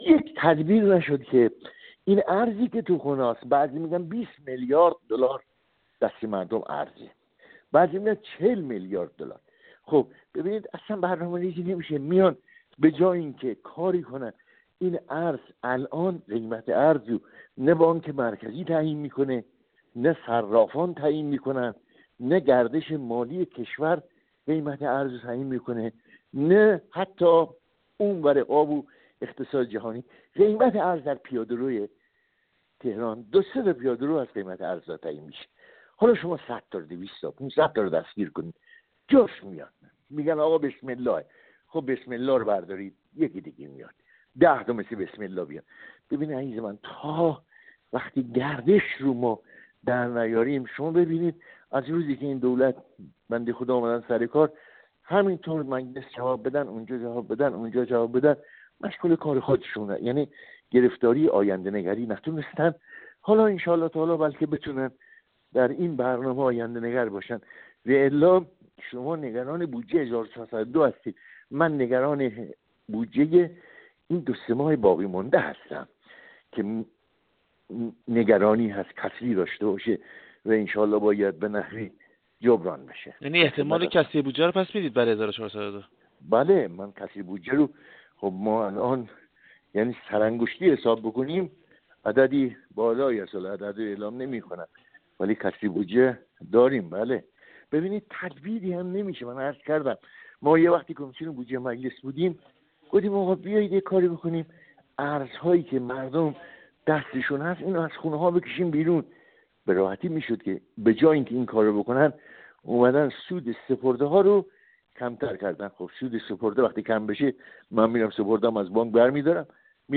یوسفیان در گفت‌وگو با ایکنا تأکید کرد: